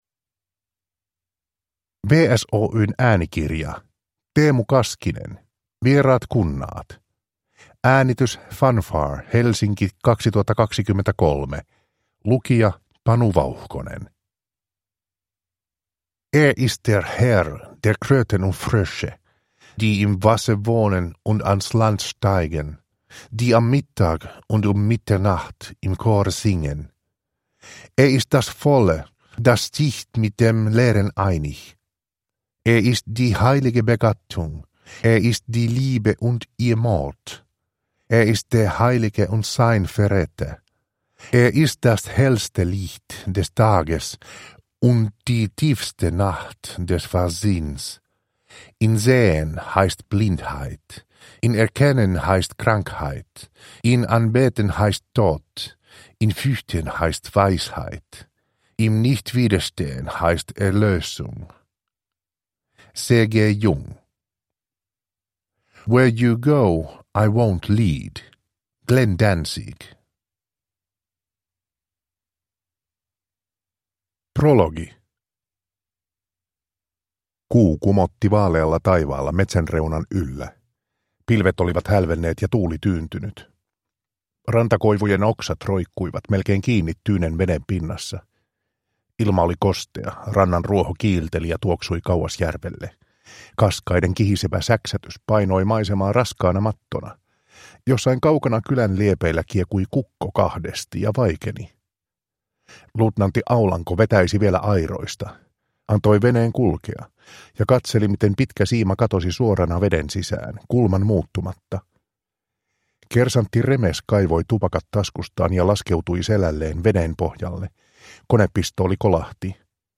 Vieraat kunnaat – Ljudbok – Laddas ner